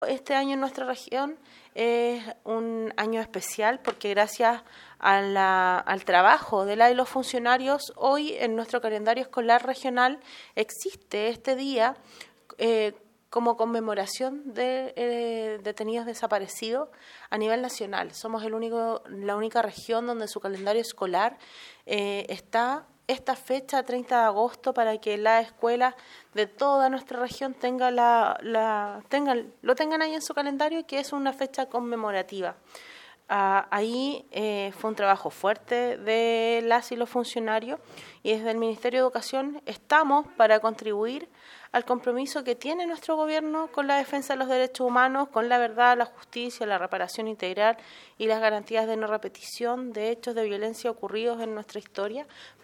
La Seremi de Educación Cecilia Ramírez Chávez destacó que
Cecilia-Ramirez-Chavez-Seremi-de-Educacion-Dia-del-Detenido-Desaparecido-en-Calendario-Escolar-Regional_.mp3